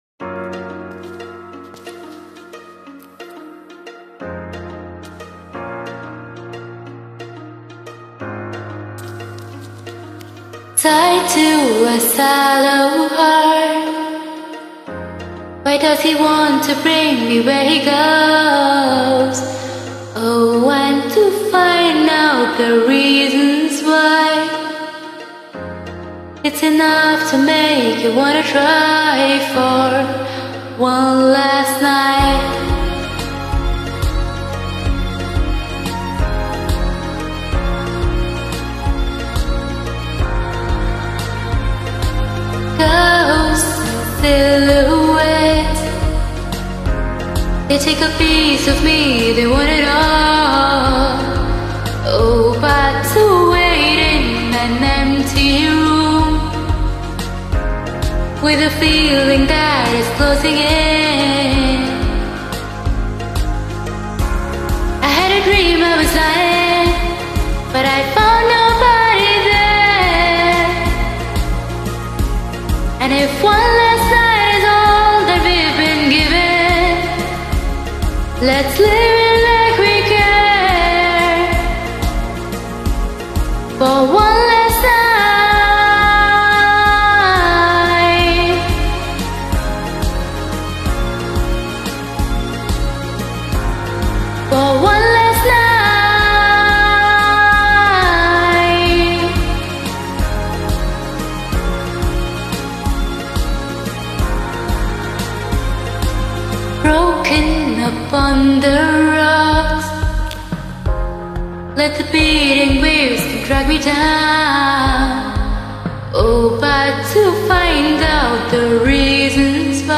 Nice Voice wtih more clarity !!